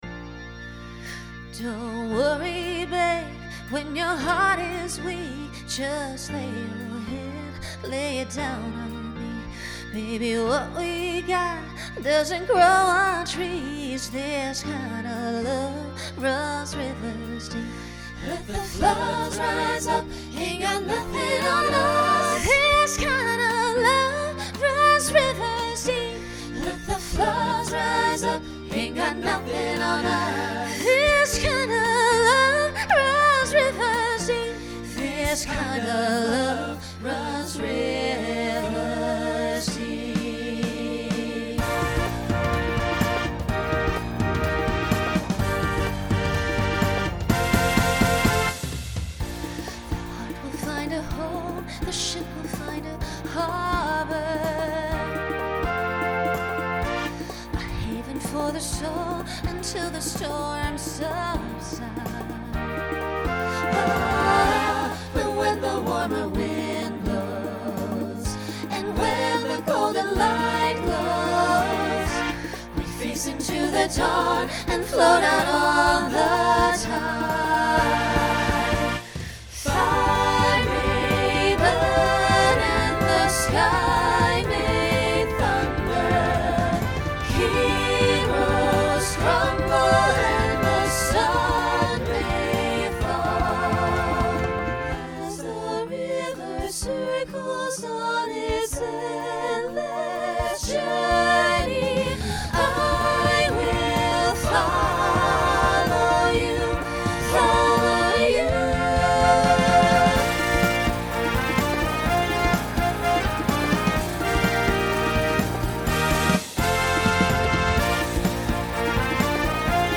Genre Rock Instrumental combo
Solo Feature Voicing SAB